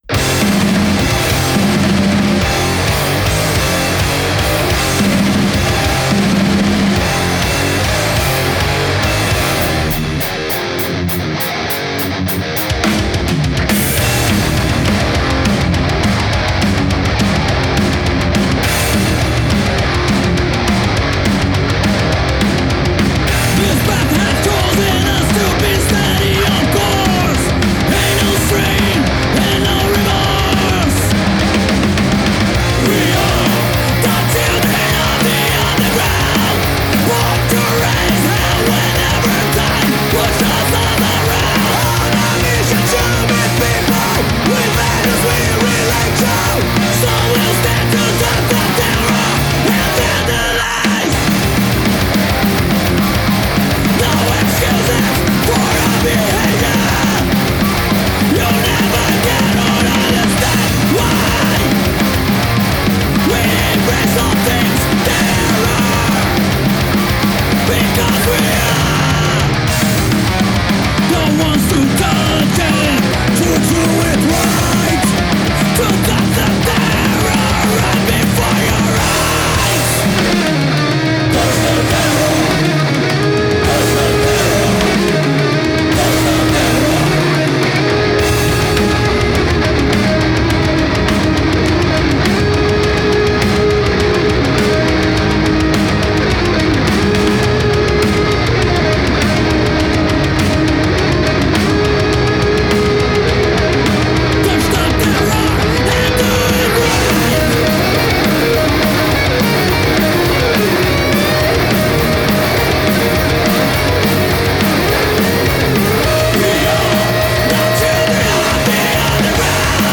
crossover thrash hardcore